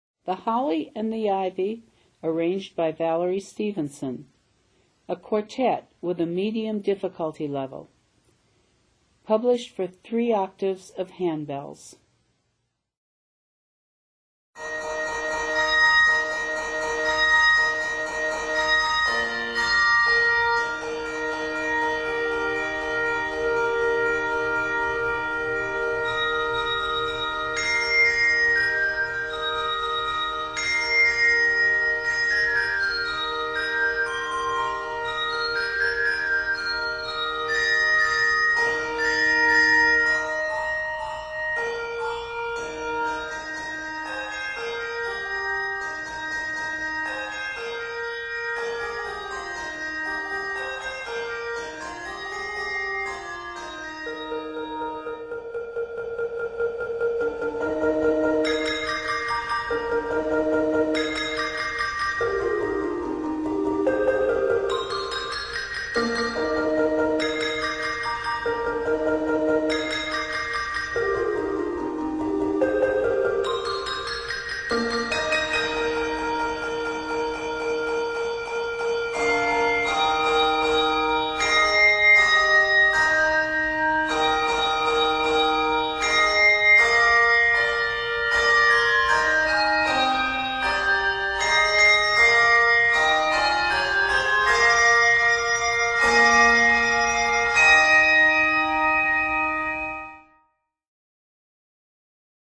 Set in Eb Major, measures total 48.
Less than a full handbell choir: Quartet